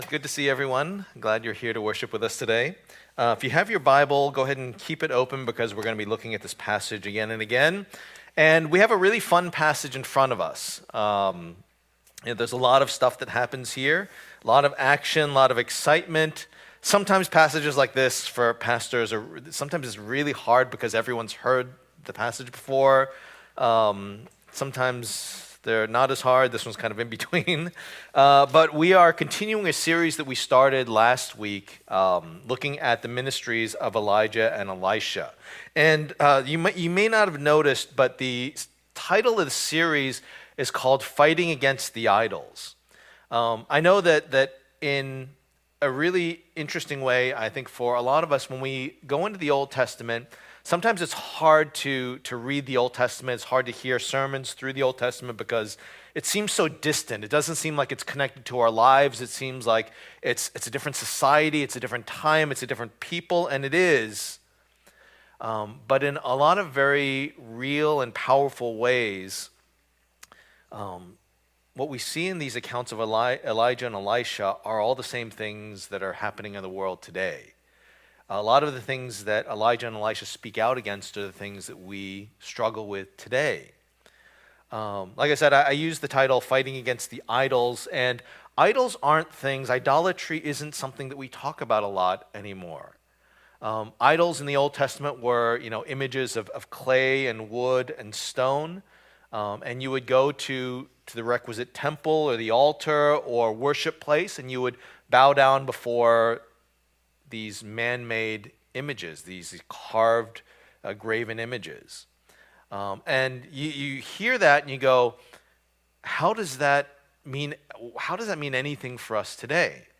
1 Kings 18:16-46 Service Type: Lord's Day %todo_render% « Now I Know I